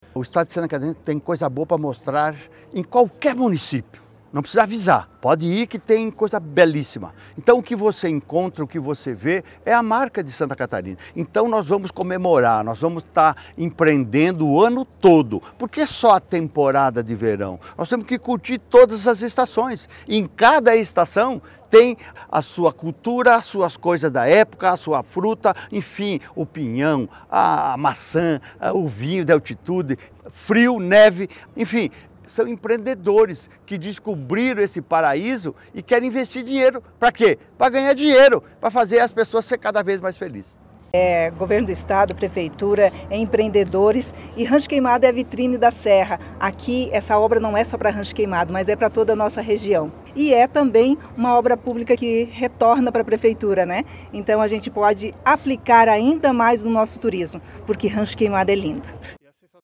O governador Jorginho Mello inaugurou nesta quinta-feira, 25, o mirante da Serra da Boa Vista, em Rancho Queimado.
O governador falou do empreendimento e ressaltou que é mais uma marca de Santa Catarina:
Para a prefeita da cidade, Cleci Veronesi, a obra é um trabalho em conjunto não só para Rancho Queimado: